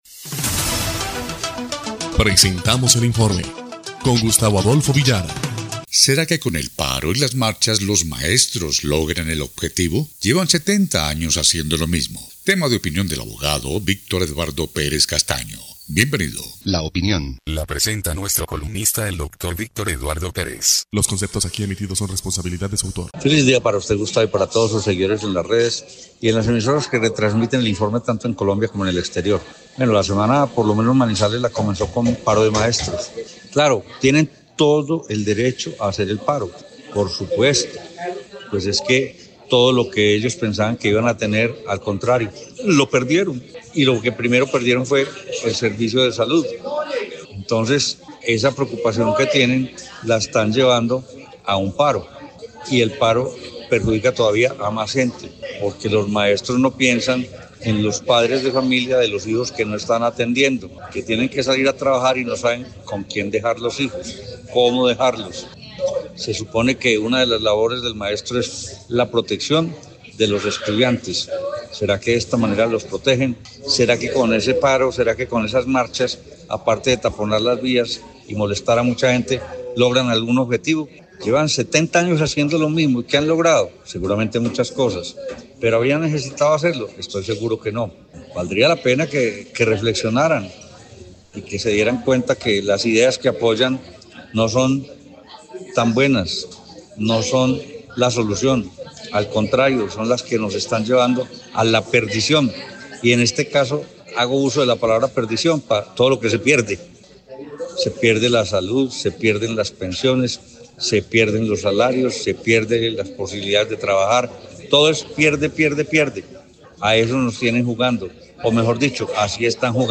EL INFORME 3° Clip de Noticias del 27 de mayo de 2025